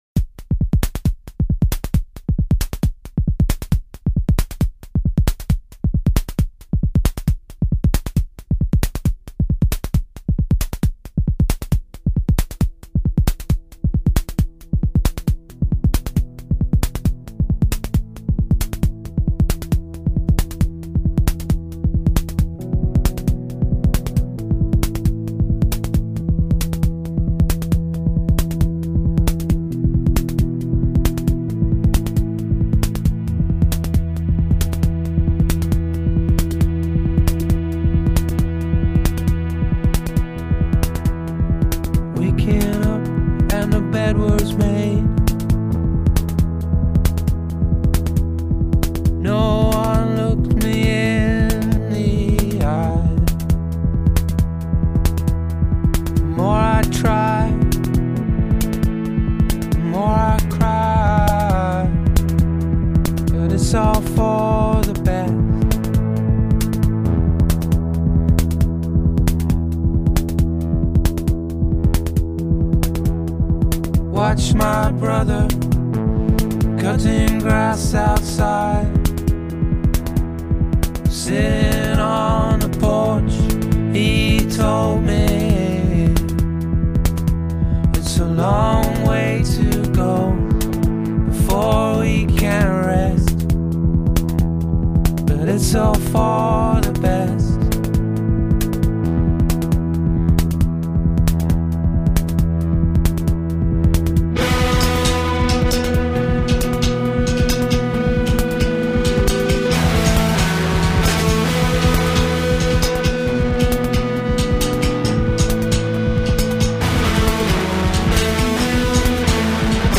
distant electronica, layered all crisp and sad and perfect